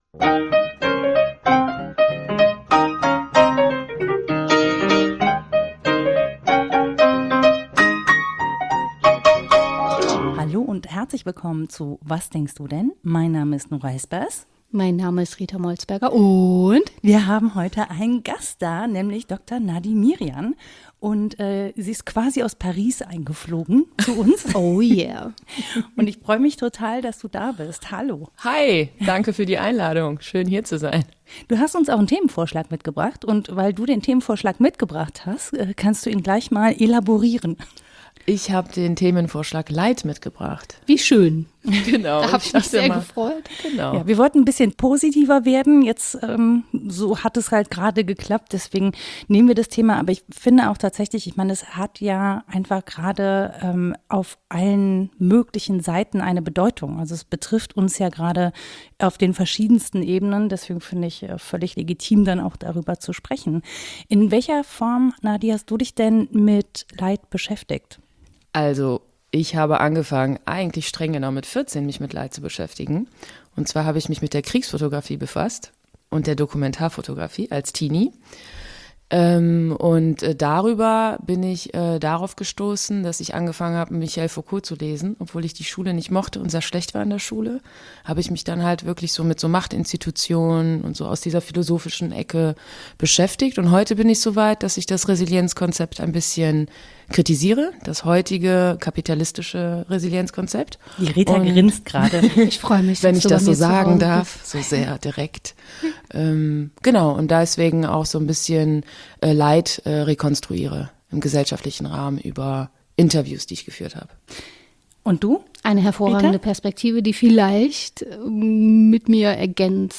Klingt bierernst, ist aber mitunter ganz schön witzig.
Wir haben einfach Spaß daran, laut zu denken.